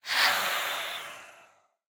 sounds / mob / phantom / idle5.ogg